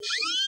SERVO SE03.wav